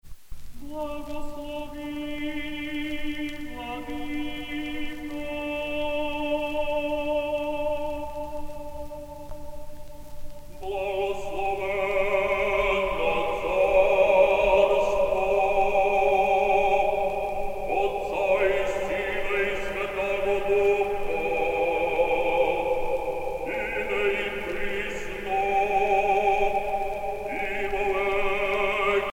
dévotion, religion
Pièce musicale éditée